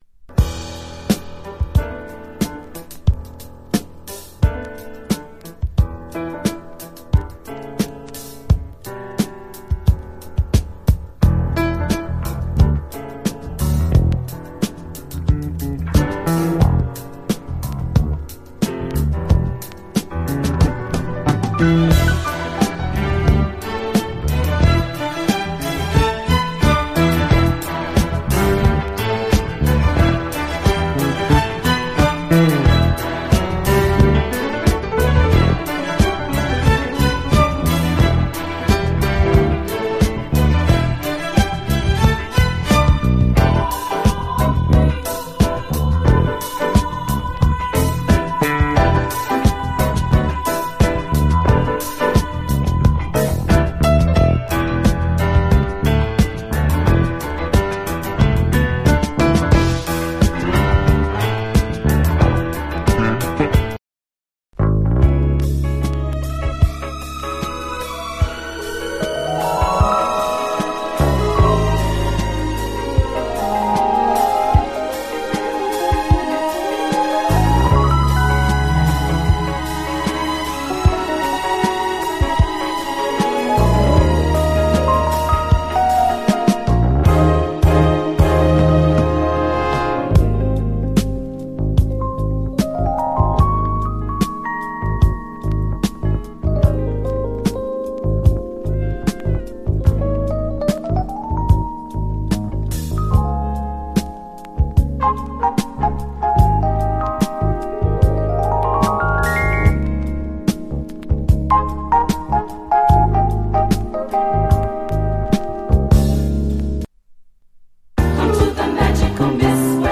JAZZ FUNK / SOUL JAZZ, JAZZ
孤高のメロウ・ジャズ・ファンク！
零れ落ちるようなエレピが美しい！キリッとタイトなジャズ・ファンク
流麗な女性コーラスが舞うメロウ・ラテン・ジャズ